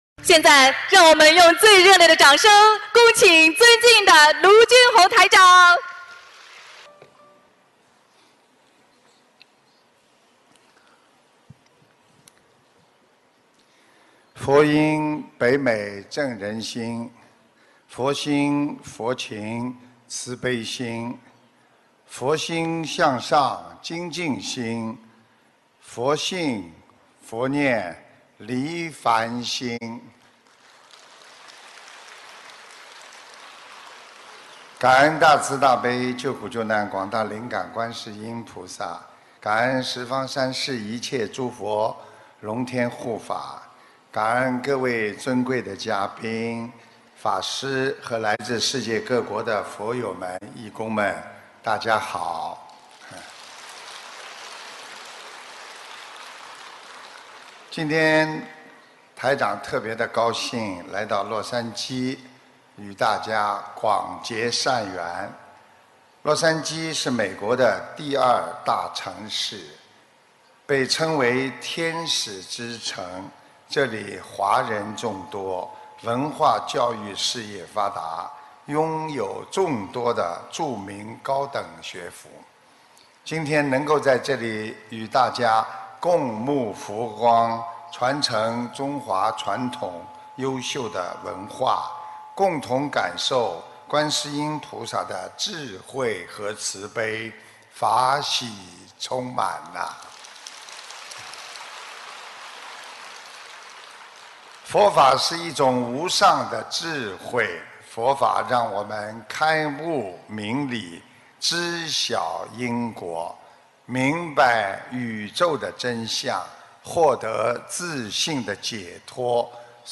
【2018年 美国洛杉矶】10月7日 大fa会 | 文字 + 音频 - 2018法会合集 慈悲妙音